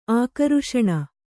♪ ākaruṣaṇa